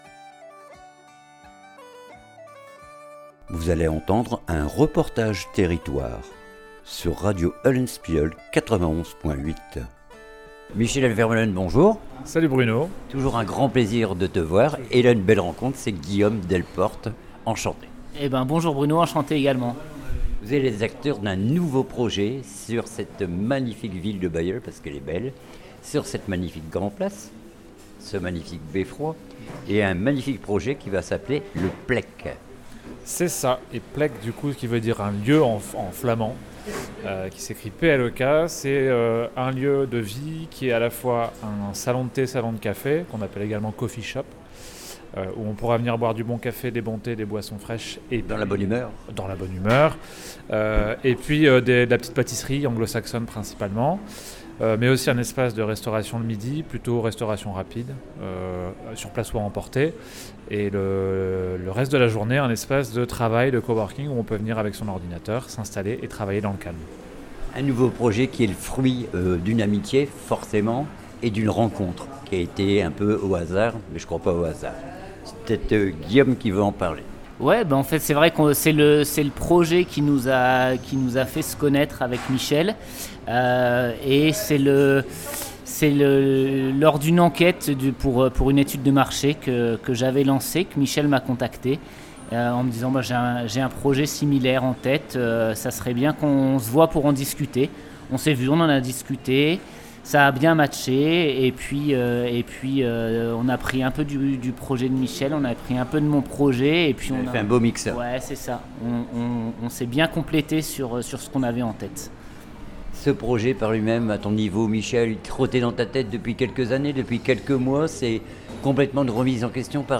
REPORTAGE TERRITOIRE PLEK BAILLEUL